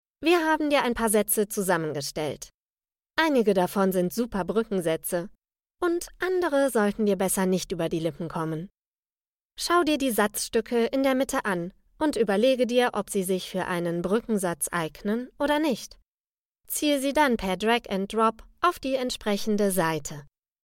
Joven, Accesible, Versátil, Cálida, Suave
E-learning